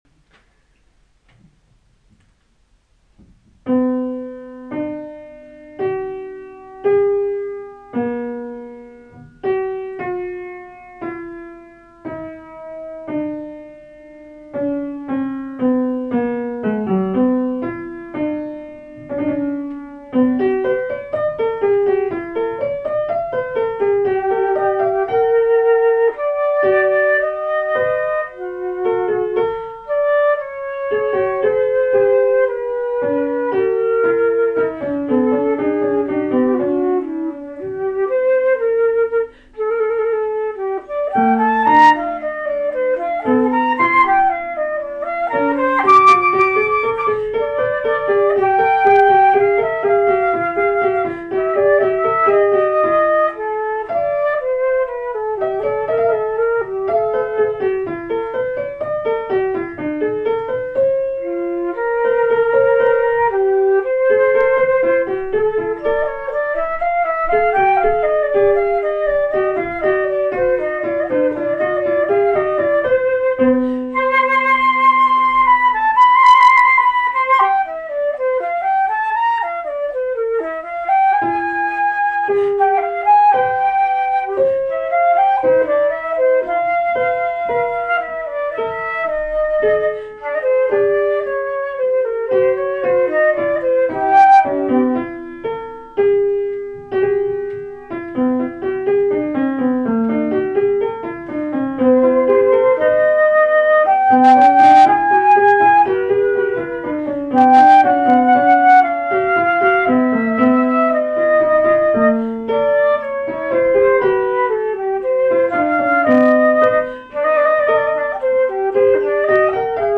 \93Fuga canonica in Epidiapente\94 means a canonical fugue at the fifth.
The result would look like this: Canon 6 animation Performance: Here is a recording of the leader played by the flute, and the follower by one hand on the piano.